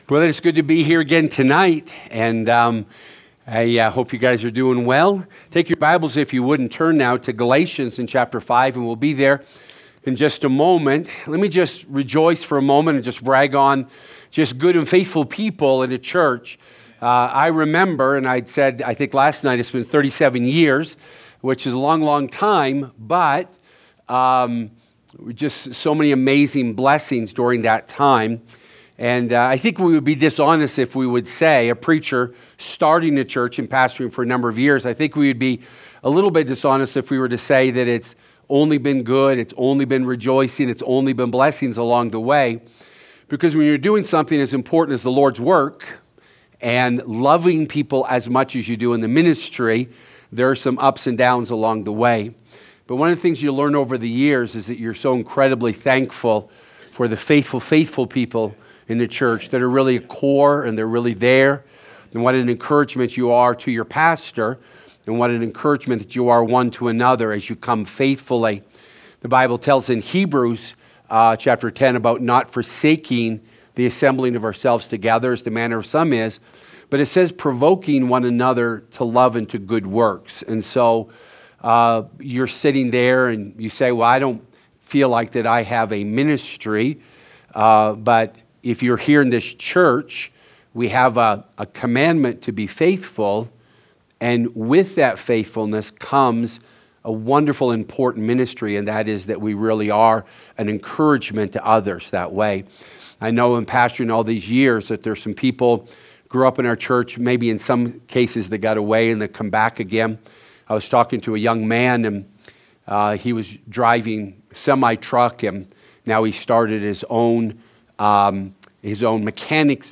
Winter Revival 2026 – The Battle in Our Walk